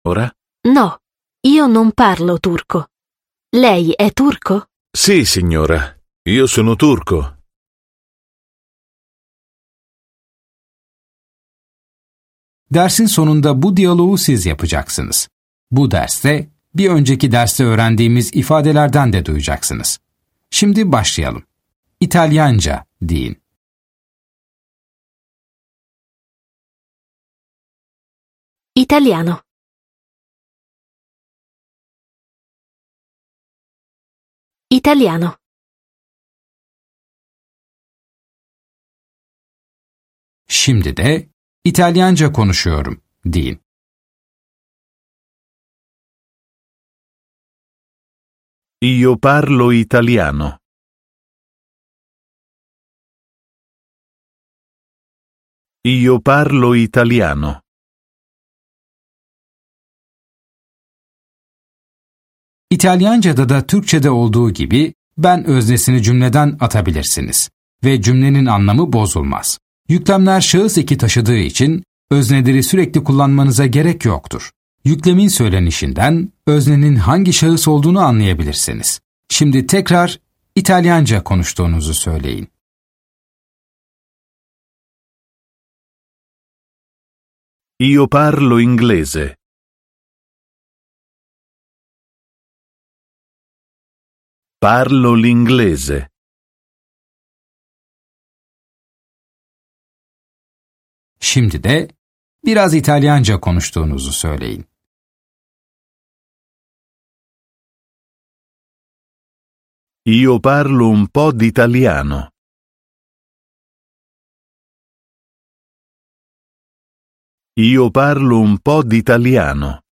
Dersler boyunca sizi yönlendiren bir kişisel eğitmeniniz olacak. Ana dili İtalyanca olan iki kişi de sürekli diyalog halinde olacaklar.